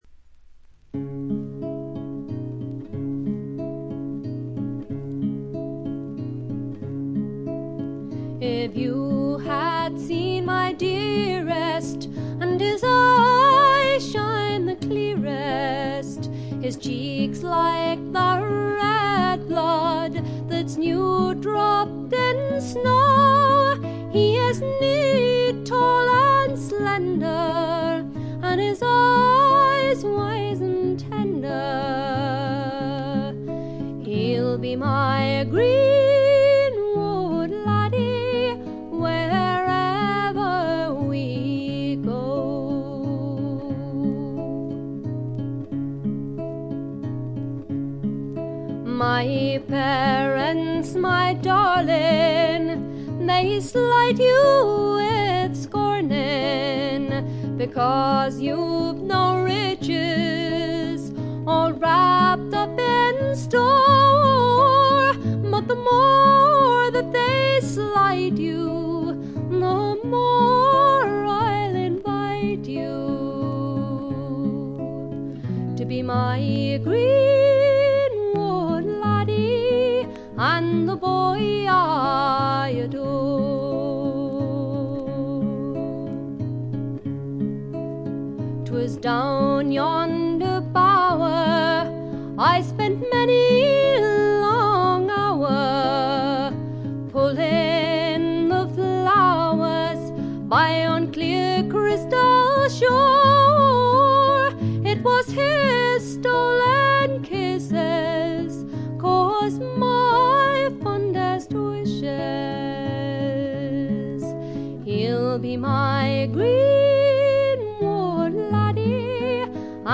特にオルガンのひなびた音色とかたまらんものがあります。
あまりトラッド臭さがなくほとんどドリーミーフォークを聴いているような感覚にさせてくれる美しい作品です。
試聴曲は現品からの取り込み音源です。
Fiddle, Recorder, Vocals